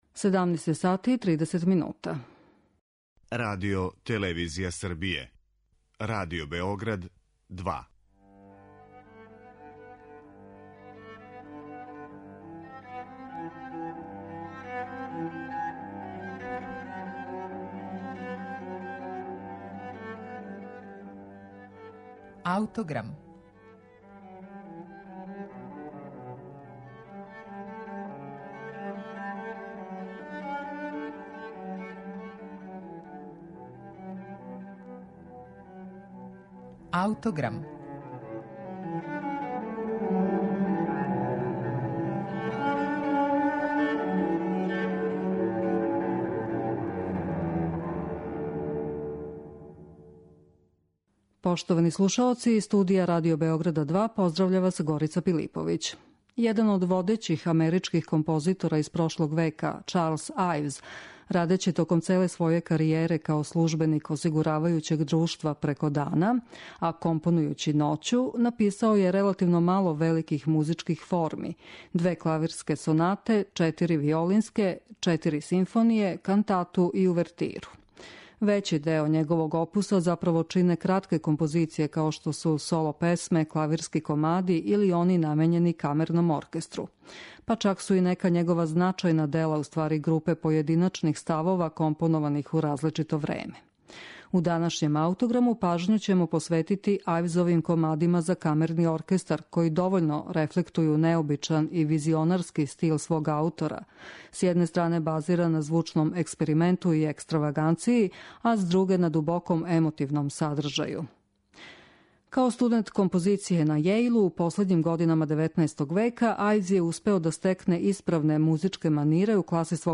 Комади за камерни оркестар Чарлса Ајвза